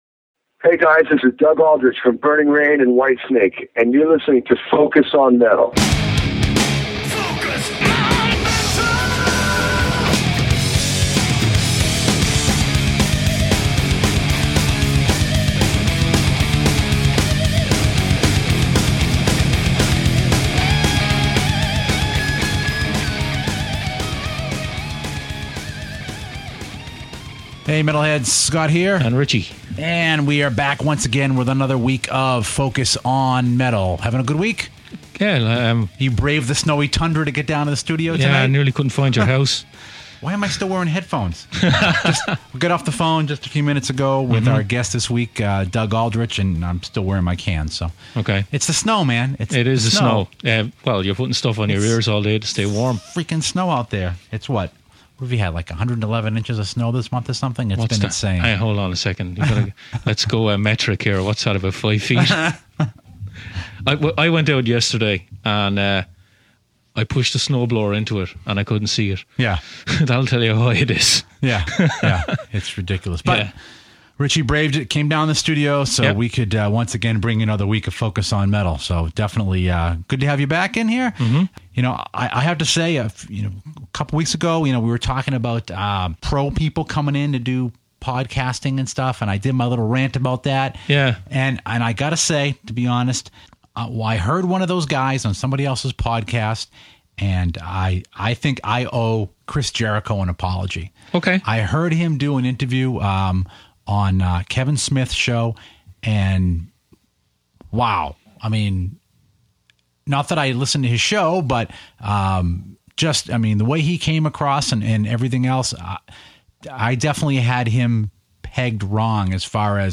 The new release from Revolution Saints drops this week and Doug Aldrich returns to the show this week to give us all the lowdown on it. Doug also gives us some insight into his decision to leave Whitesnake as well as an update on what’s going on with Burning Rain and his hometown gig with Raiding The Rock Vault.